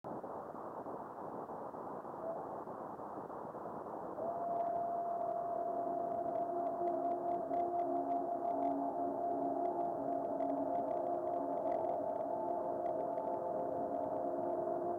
dual frequency forward scatter system;   video and stereo sound:
Good head echo amidst some interference.  This movie is of the head echo only.